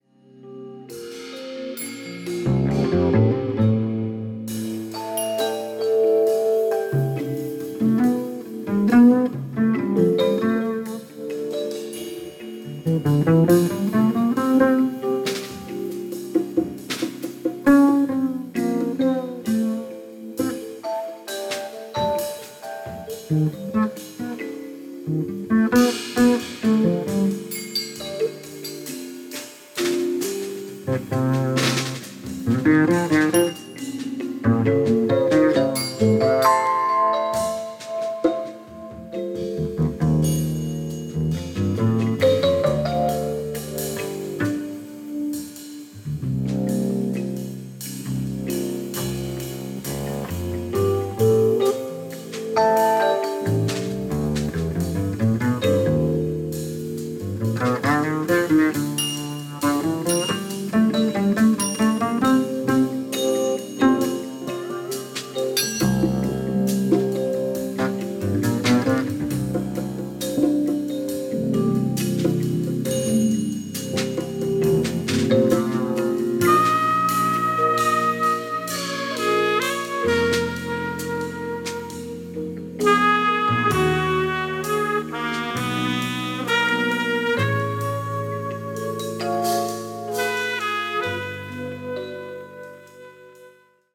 Bass
Drums
Saxophone
Trumpet